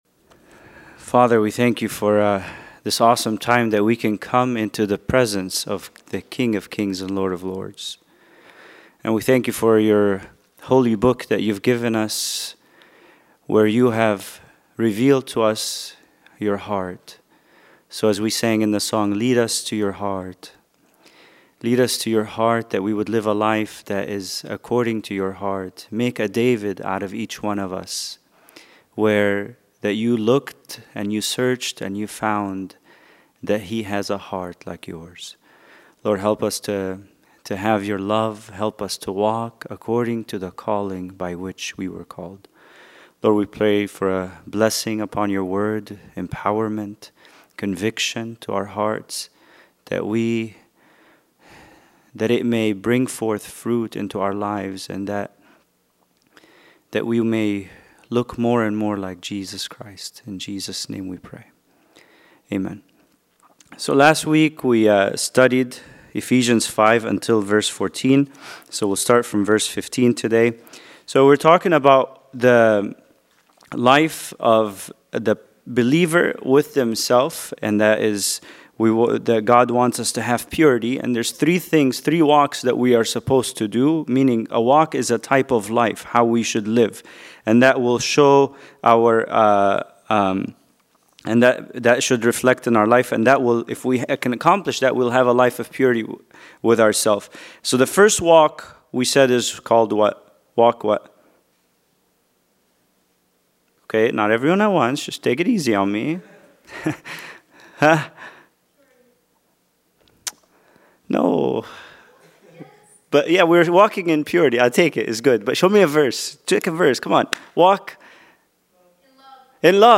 Bible Study: Ephesians 5:15-17